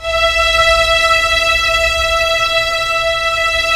Index of /90_sSampleCDs/Roland LCDP13 String Sections/STR_Symphonic/STR_Symph. Slow